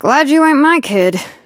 belle_kill_vo_02.ogg